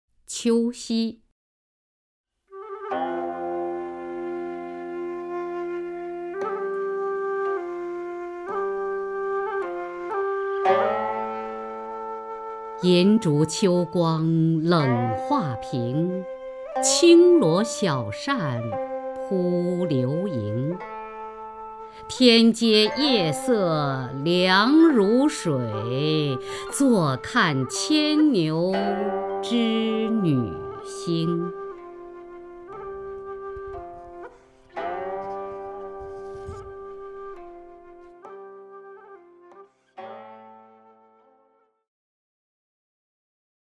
雅坤朗诵：《秋夕》(（唐）杜牧) （唐）杜牧 名家朗诵欣赏雅坤 语文PLUS